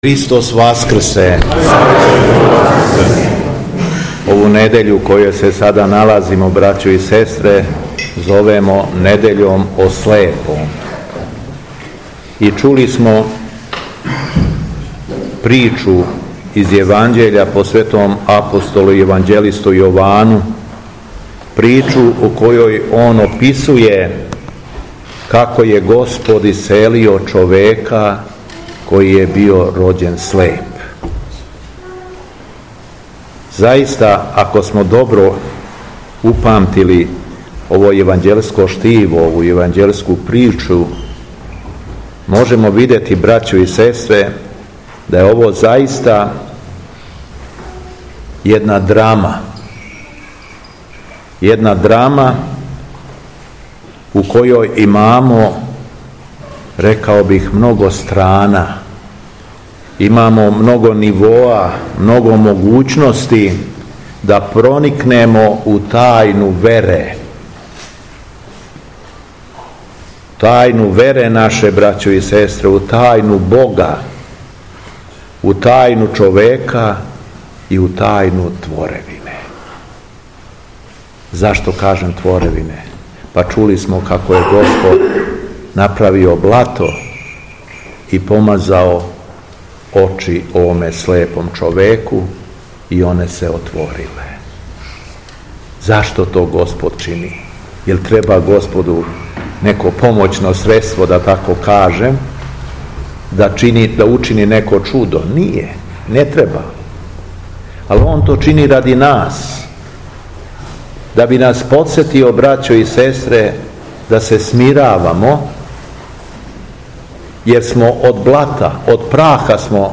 Духовна порука Његовог Високопреосвештенства Митрополита шумадијског г. Јована